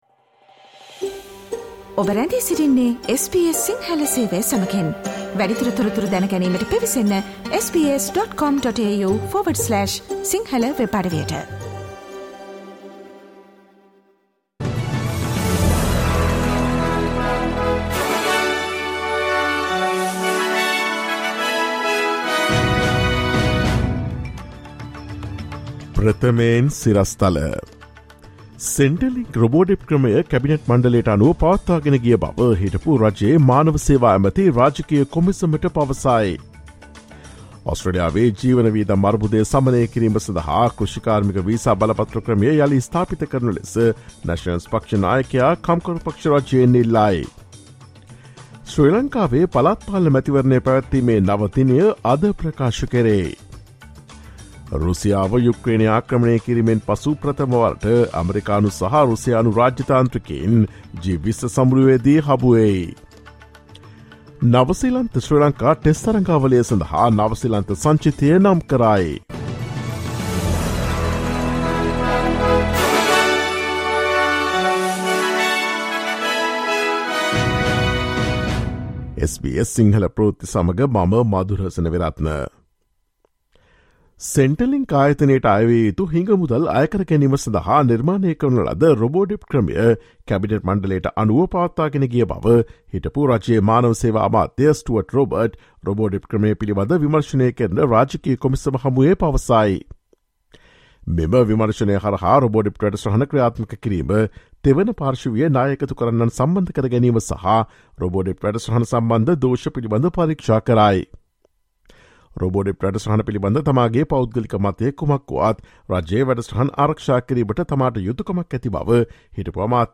ඔස්ට්‍රේලියාවේ සහ ශ්‍රී ලංකාවේ නවතම පුවත් මෙන්ම විදෙස් පුවත් සහ ක්‍රීඩා පුවත් රැගත් SBS සිංහල සේවයේ 2023 මාර්තු 03 වන දා සිකුරාදා වැඩසටහනේ ප්‍රවෘත්ති ප්‍රකාශයට සවන් දෙන්න.